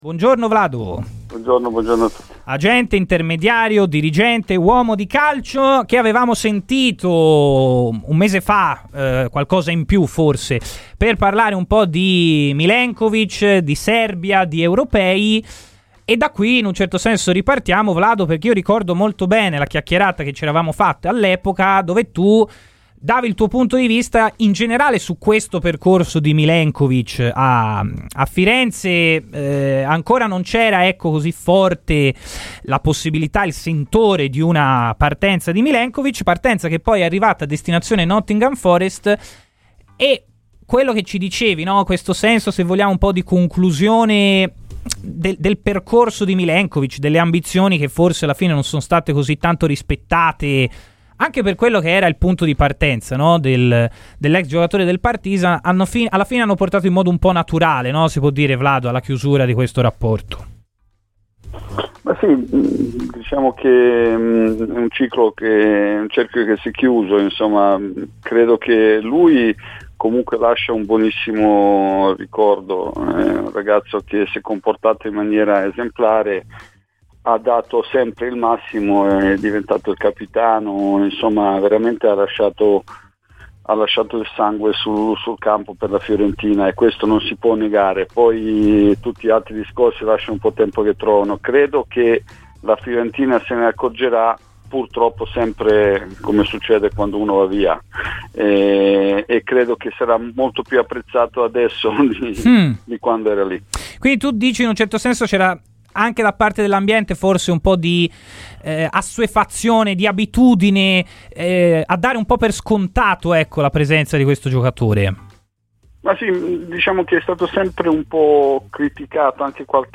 in diretta su Radio FirenzeViola